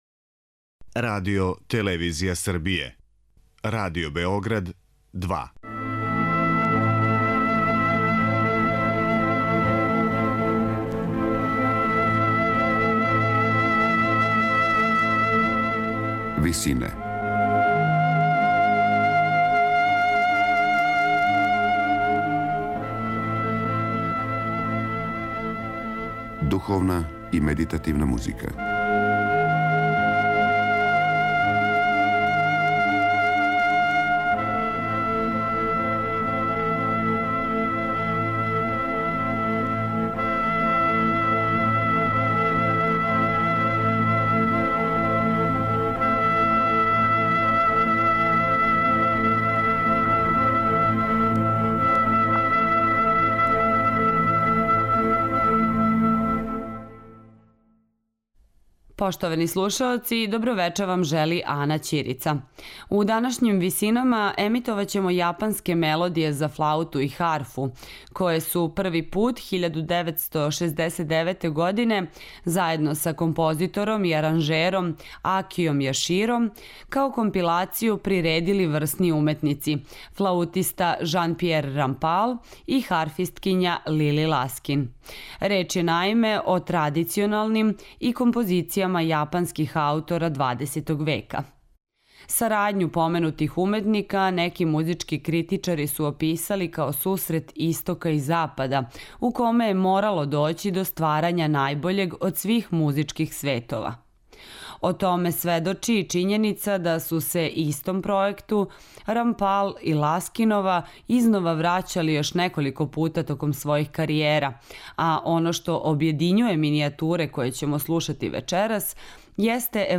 Слушаћете јапанске мелодије
флаутиста
харфисткиња